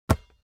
دانلود صدای تصادف 53 از ساعد نیوز با لینک مستقیم و کیفیت بالا
جلوه های صوتی